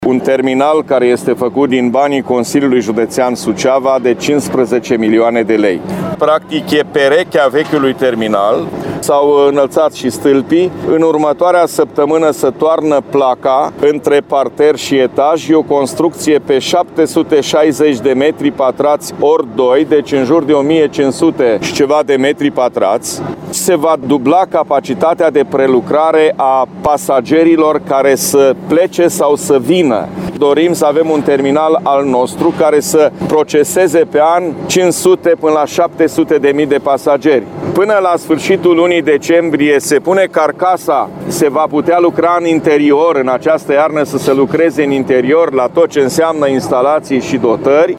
Președintele Consiliului Județean GHEORGHE FLUTUR a detaliat astăzi stadiul lucrărilor la terminal.